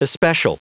Prononciation du mot especial en anglais (fichier audio)
Prononciation du mot : especial